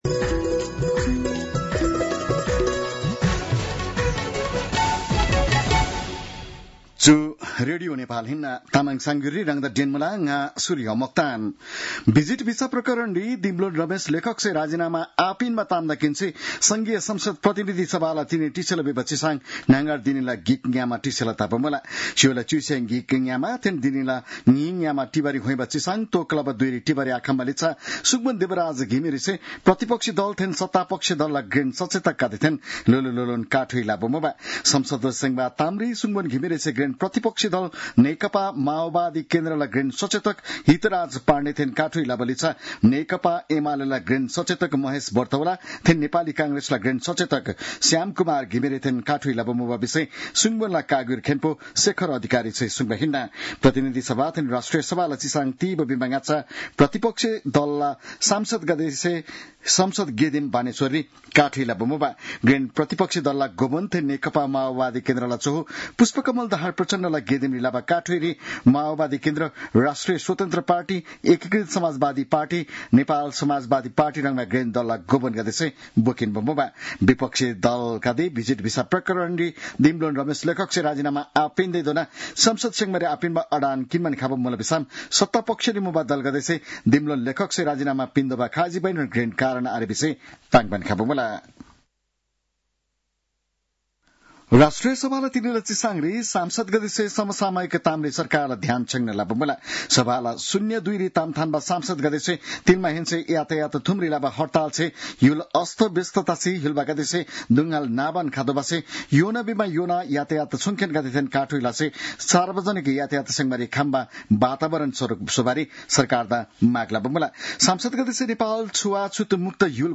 तामाङ भाषाको समाचार : २० जेठ , २०८२